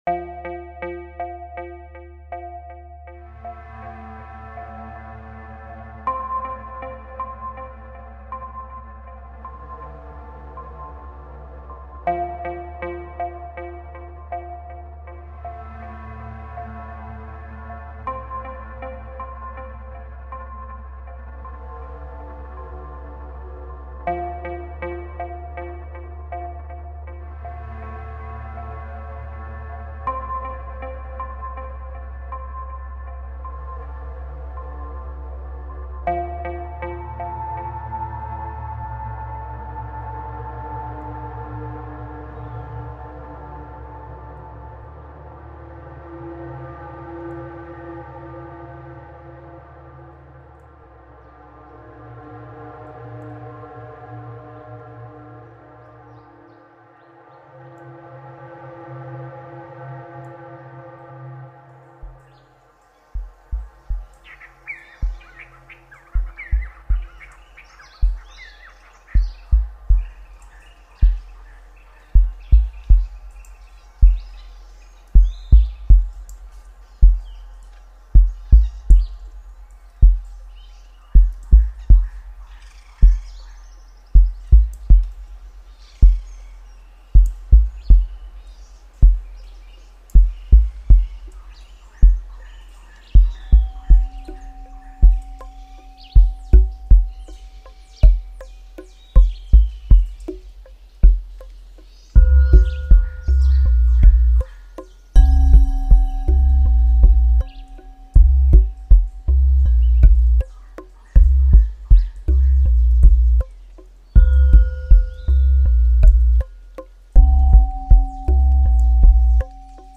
Meditation & Relaxation Music: